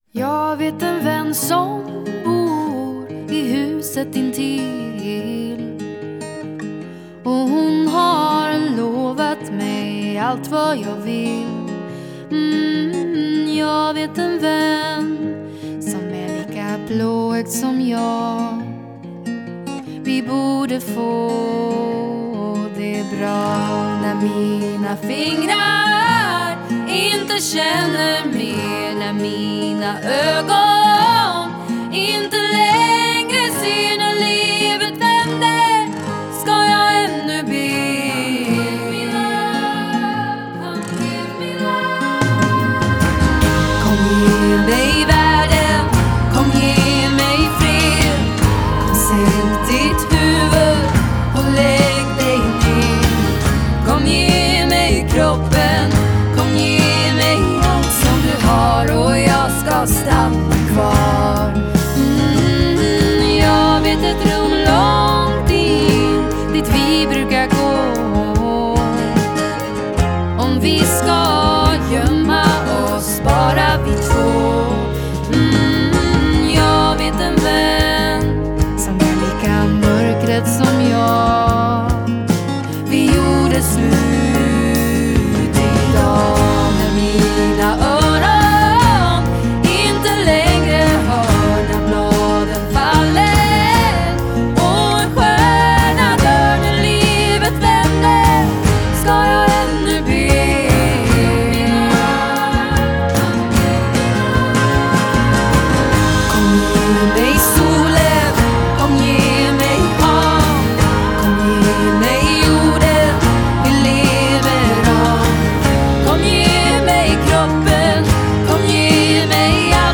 Жанр: Indie Pop
Genre - Indie Pop, Singer-Songwriter